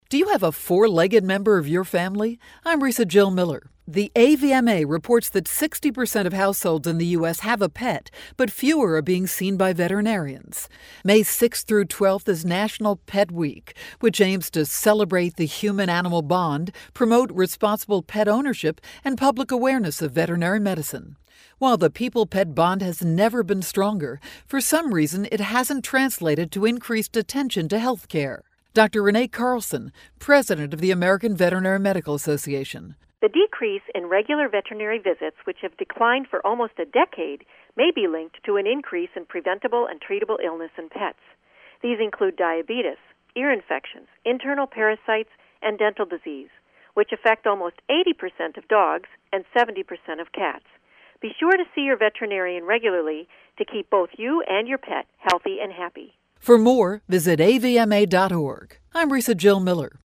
May 4, 2012Posted in: Audio News Release